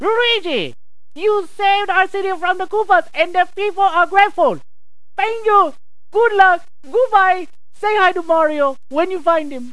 The Mayor O. K. Leeder voice clip in Mario is Missing! CD-ROM Deluxe